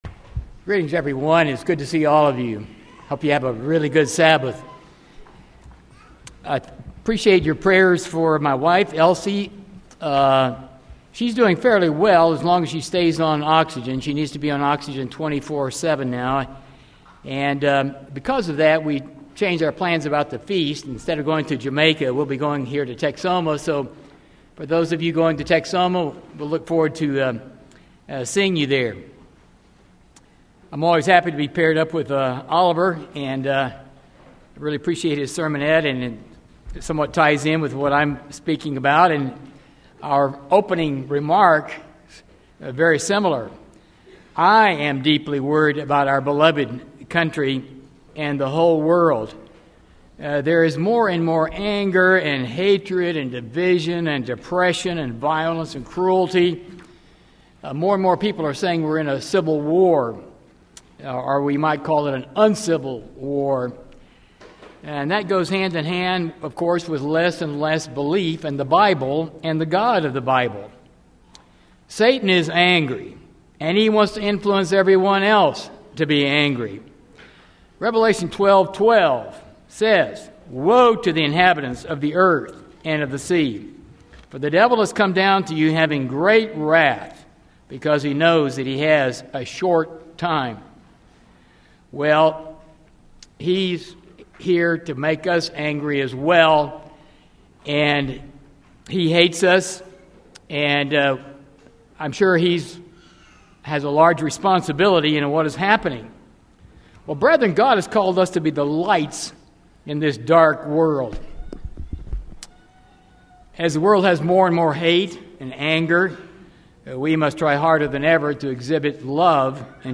In this sermon, joy, happiness and contentment are used almost synonymously. A happy person benefits himself and everyone around him.